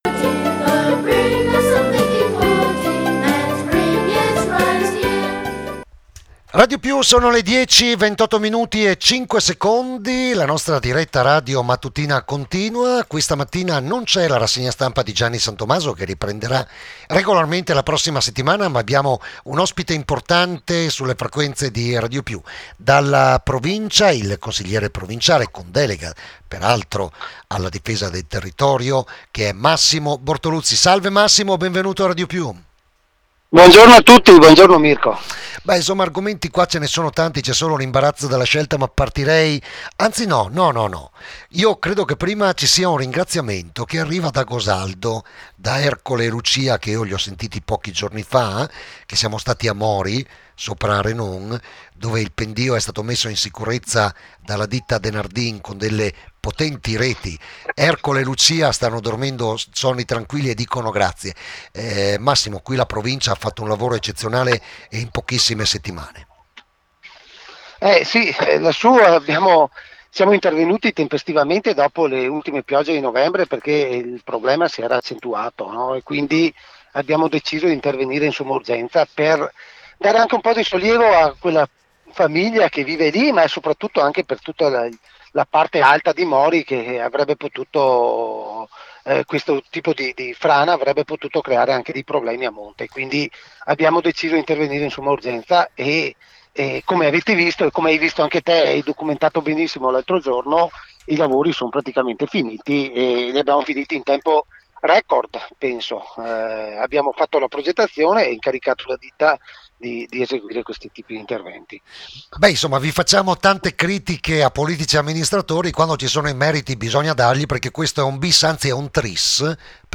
diretta radio INTERVISTA A MASSIMO BORTOLUZZI CONSIGLIERE PROVINCIA